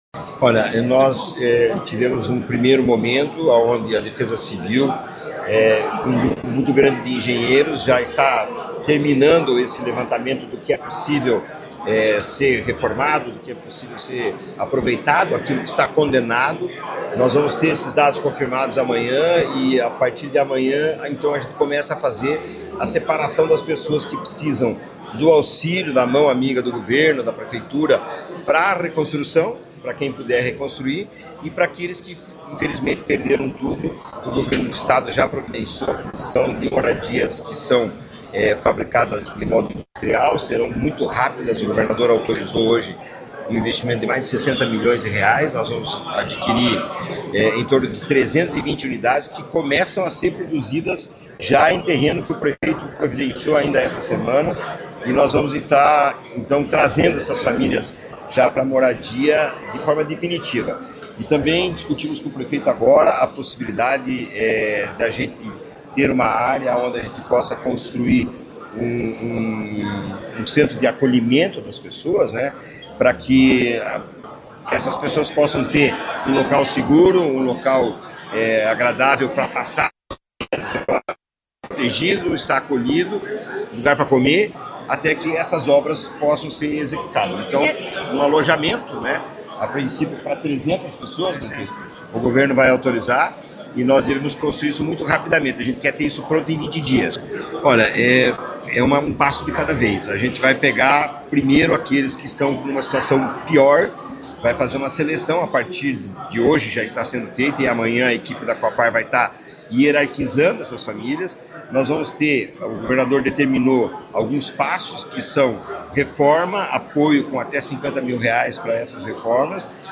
Sonora do presidente da Cohapar, Jorge Lange, sobre as iniciativas de reconstrução para a comunidade de Rio Bonito do Iguaçu